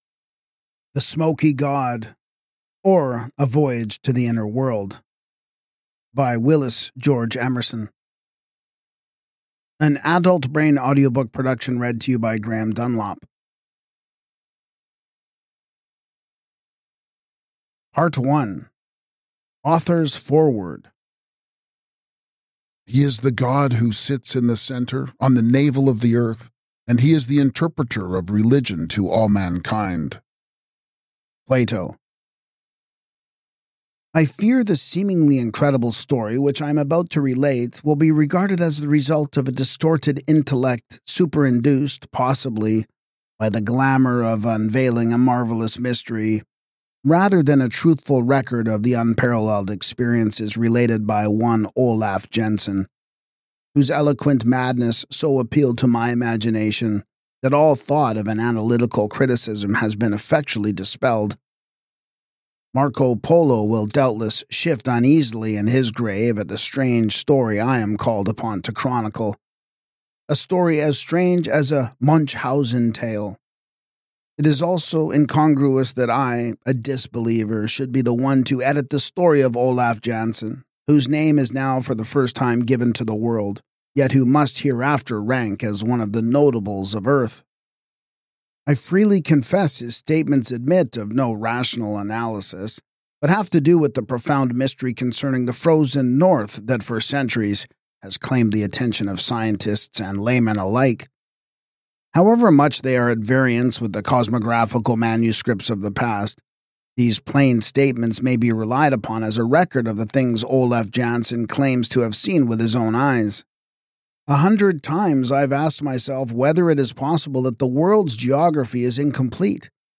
Now brought to life in audiobook form, The Smoky God is more than a story—it is an invitation to explore the unknown and consider what wonders might lie hidden beneath our very feet.